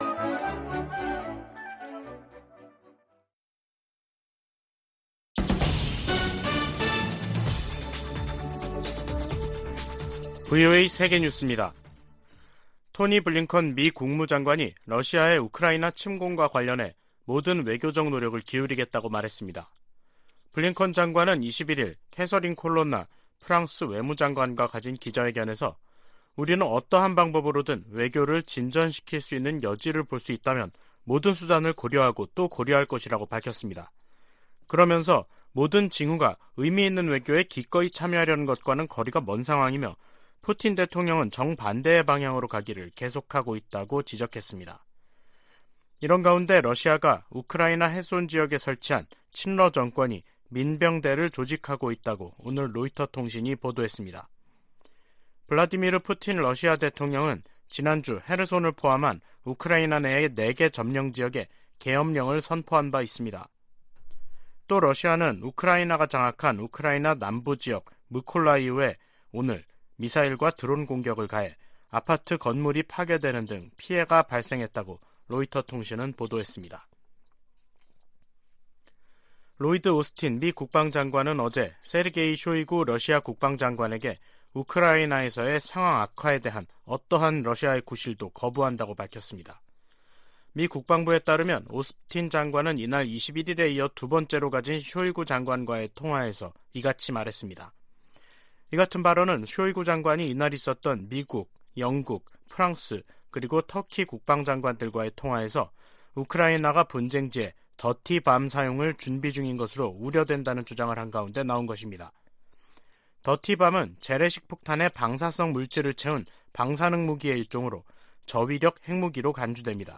VOA 한국어 간판 뉴스 프로그램 '뉴스 투데이', 2022년 10월 24일 3부 방송입니다. 한국 합동참모본부는 24일 서해 백령도 서북방에서 북한 상선이 북방한계선(NLL)을 침범해 경고 통신과 경고사격으로 퇴거 조치했다고 밝혔습니다. 북대서양조약기구는 한국과 함께 사이버 방어와 비확산 등 공통의 안보 도전에 대응하기 위해 관계를 강화하는데 전념하고 있다고 밝혔습니다. 국제자금세탁방지기구가 북한을 11년째 대응조치를 요하는 '고위험 국가'에 포함했습니다.